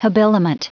Prononciation du mot habiliment en anglais (fichier audio)